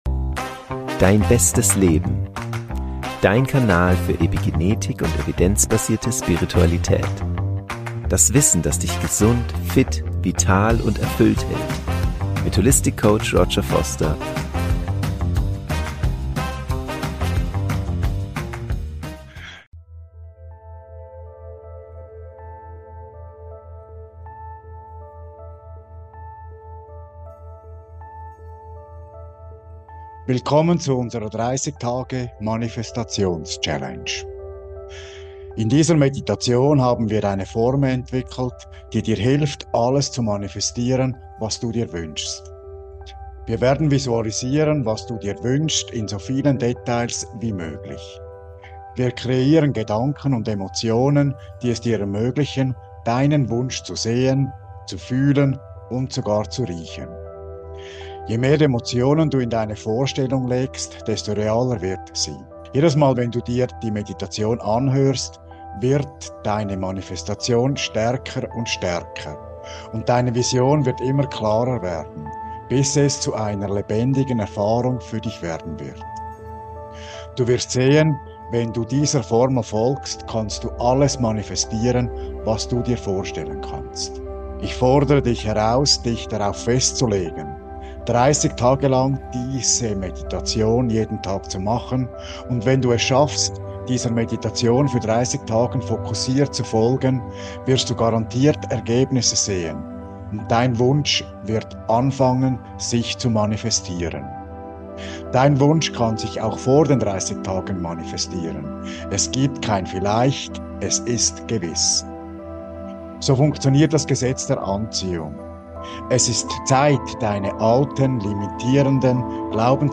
Führe dazu 30 Tage in Folge diese geführte Meditation mit binauralen Beats auf der Theta-Frequenz (4 Hz) durch.
Verwende Kopfhörer, damit Du von der Wirkung der binauralen Beats profitieren kannst.